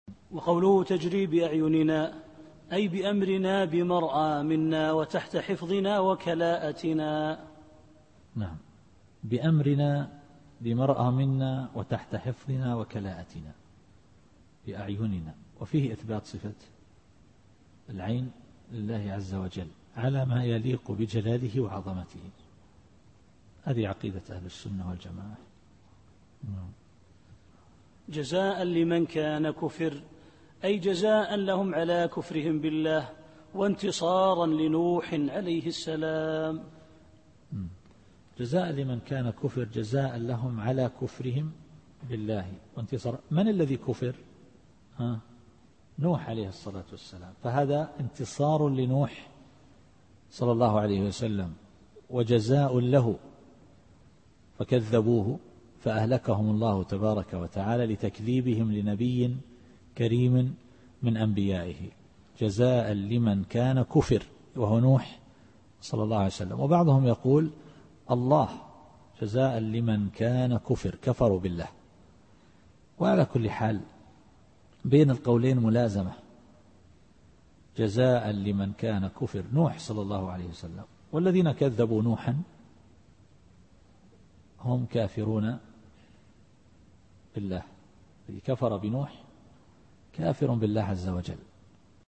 التفسير الصوتي [القمر / 14]